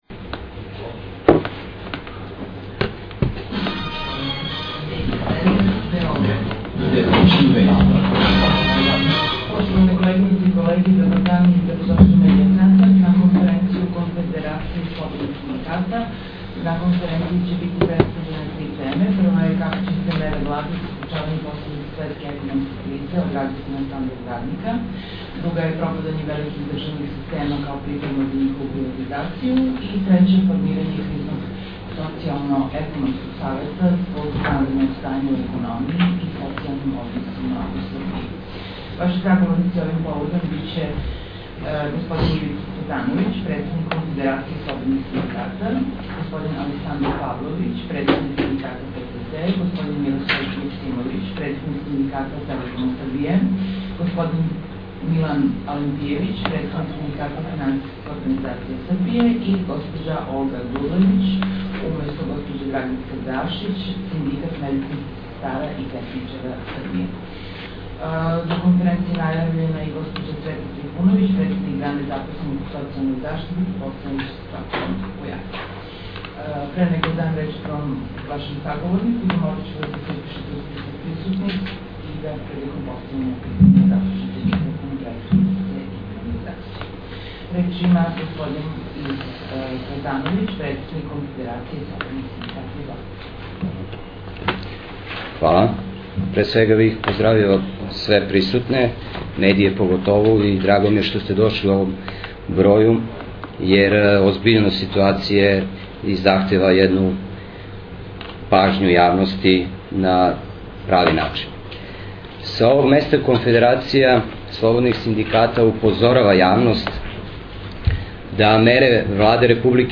КОНФЕРЕНЦИЈA ЗА НОВИНАРЕ КСС - АУДИО И ВИДЕО ЗАПИС
КОНФЕРЕНЦИЈ A ЗА НОВИНАРЕ КСС - АУДИО И ВИДЕО ЗАПИС Конференција је одржана 30.6.2009. са почетком у 10 часова у Медија центру у Београду.